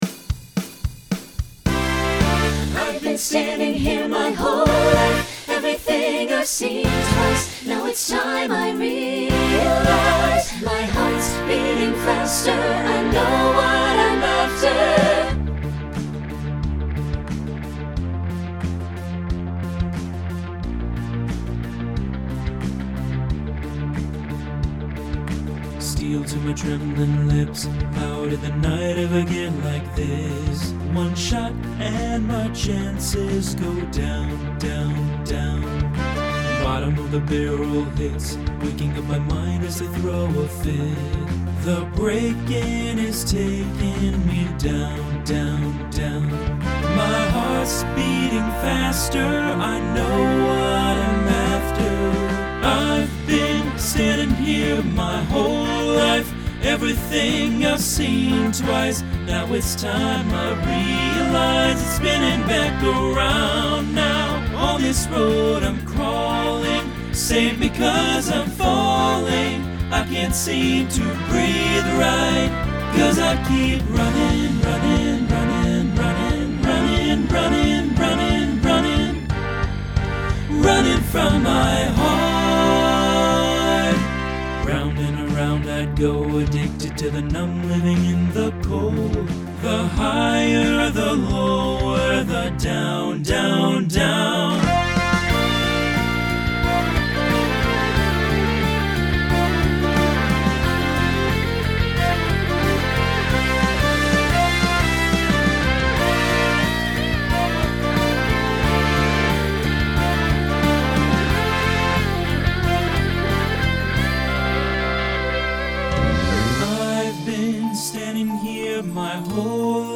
Genre Pop/Dance , Rock Instrumental combo
Transition Voicing Mixed